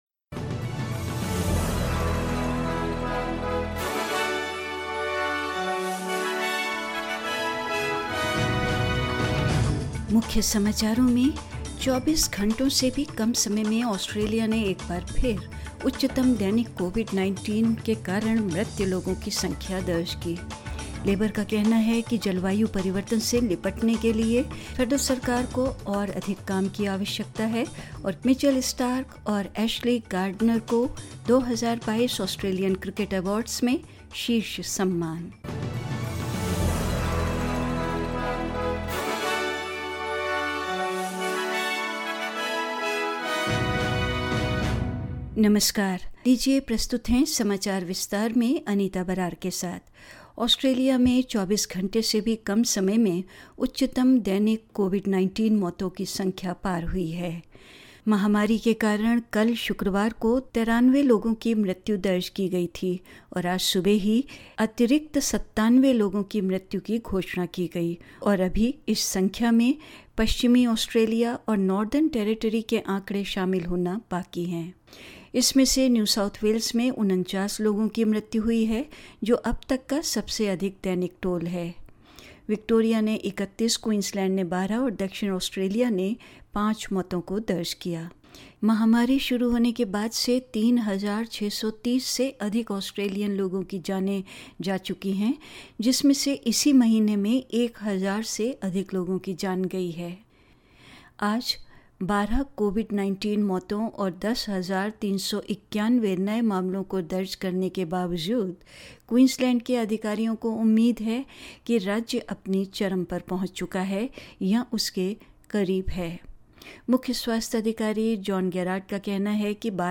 In this latest SBS Hindi bulletin: Australia has taken less than 24 hours to again surpass its highest daily total of COVID-19 deaths; Labor says the federal government needs to go further in tackling climate change; Mitchell Starc and Ashleigh Gardner have taken top honours at the 2022 Australian Cricket Awards and more news,